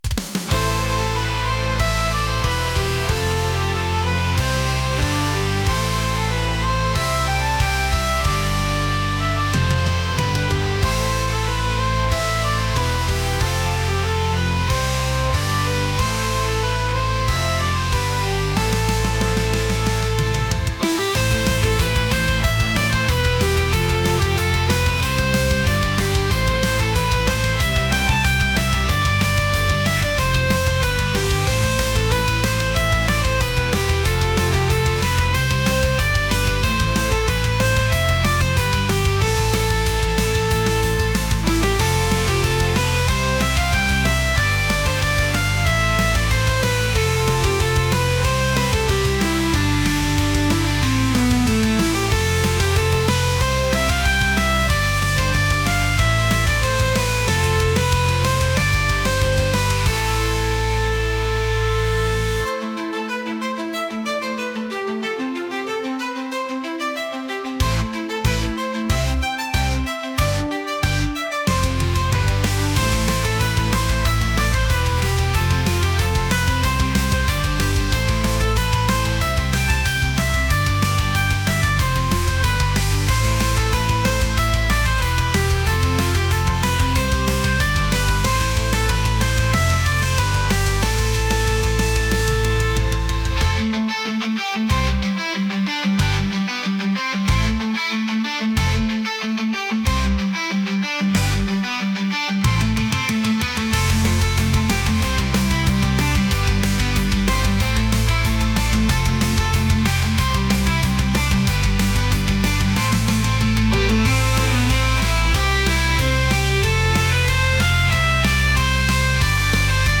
heavy | metal